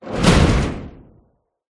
Звуки дверей
Старая железная дверь захлопнулась